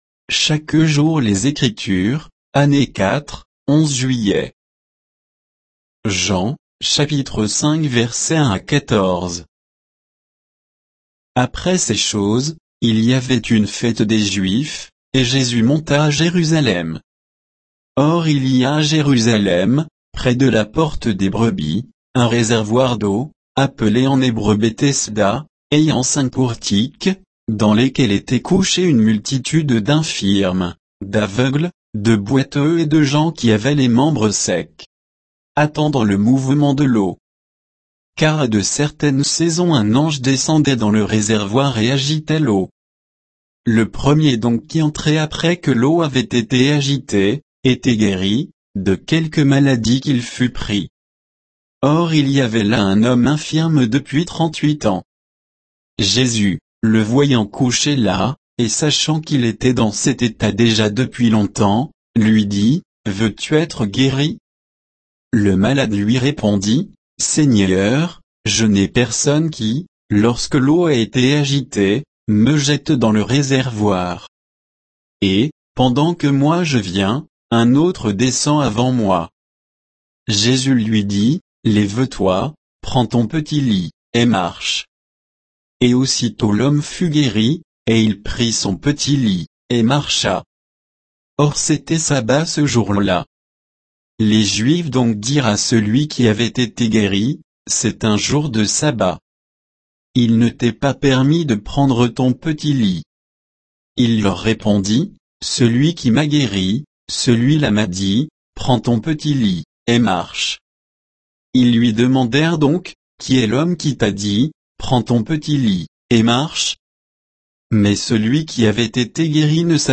Méditation quoditienne de Chaque jour les Écritures sur Jean 5